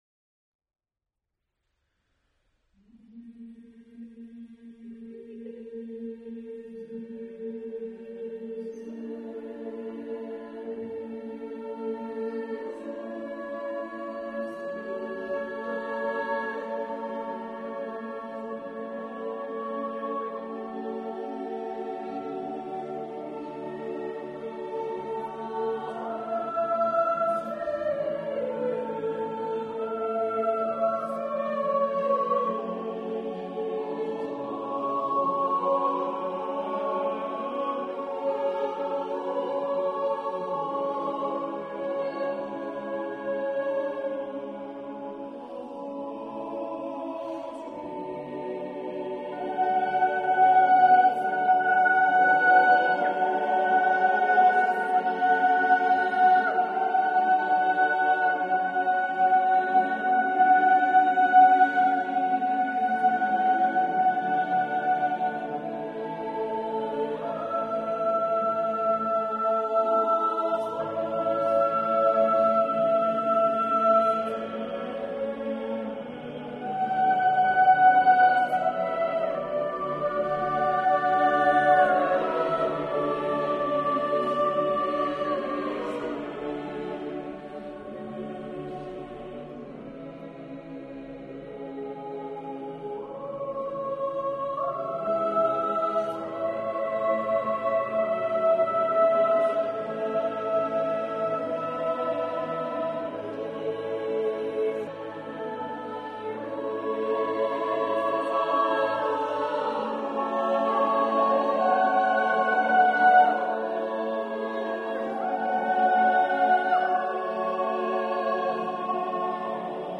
Cherwell Singers Concert March 2007
The seven-part Miserere shows phenomenal technical skill, with all bar one of the parts being in canon, either in unison, retrograde, inversion, or diminution.
Soprano Alto Tenor Bass